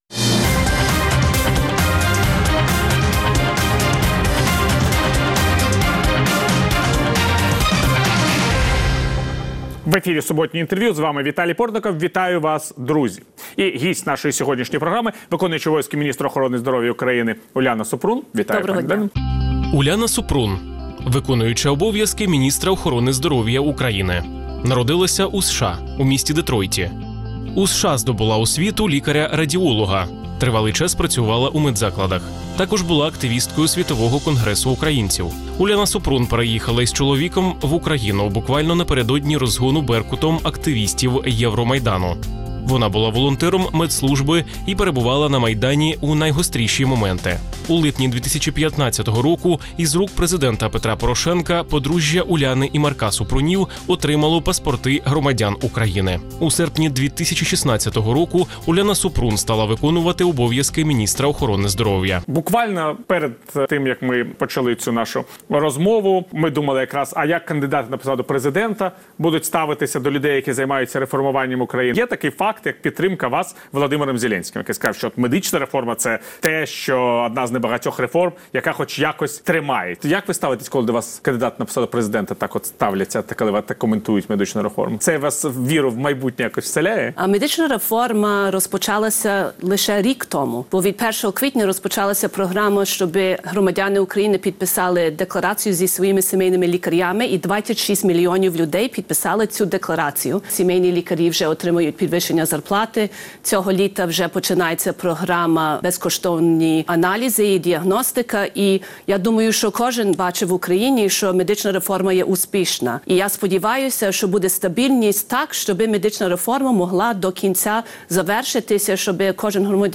Суботнє інтерв’ю | Уляна Супрун, в.о. міністра охорони здоров’я
Суботнє інтвер’ю - розмова про актуальні проблеми тижня. Гість відповідає, в першу чергу, на запитання друзів Радіо Свобода у Фейсбуці